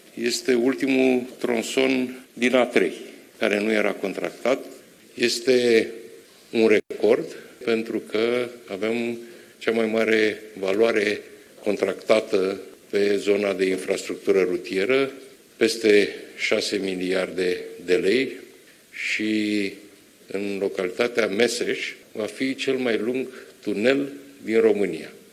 Contractul pentru executia acestui tronson de 41 de kilometri a fost semnat la Zalau, in prezenta premierului Marcel Ciolacu: